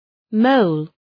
{məʋl}